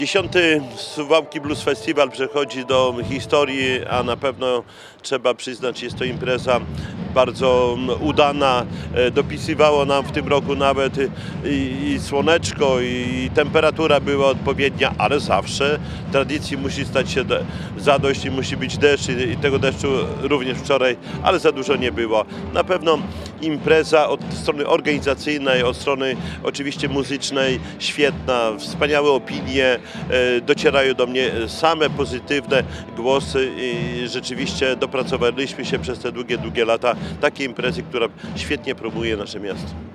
Jak mówił Czesław Renkiewicz, prezydent miasta, tym razem dopisała nawet pogoda.